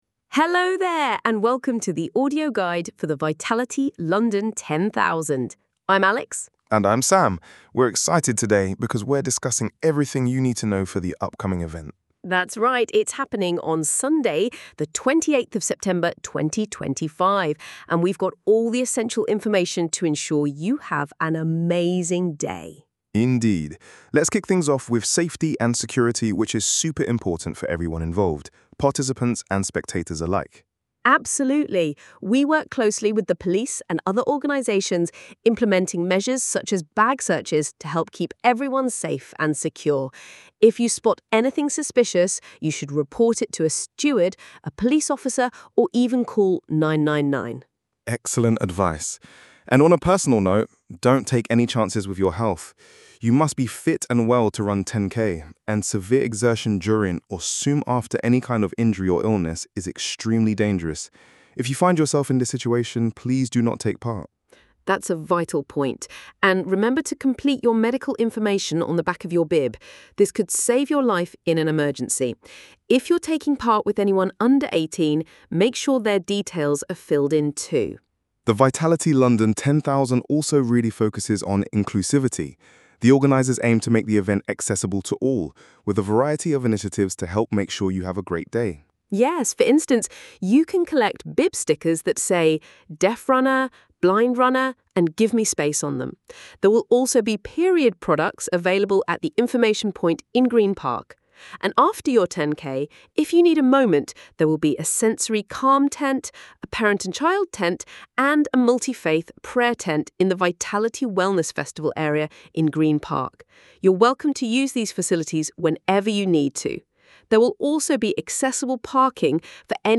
Try the new audio version Listen now The audio version of the Participant Guide was created using AI-generated audio.